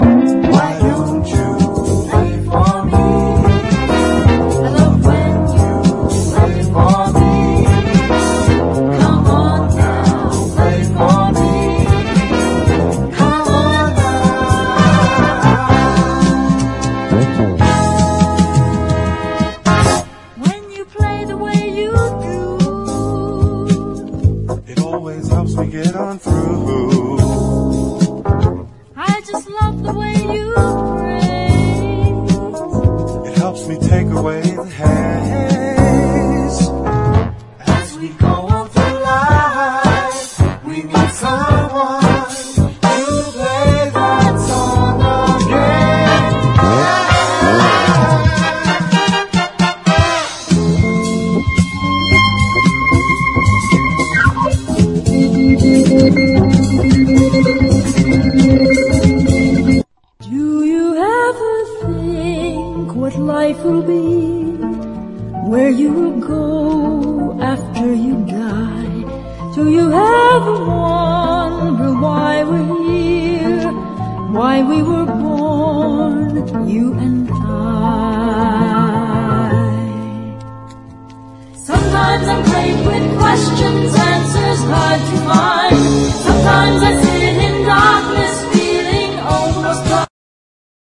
オーストリアのフリー・インプロ/エクスペリメンタル/実験音楽！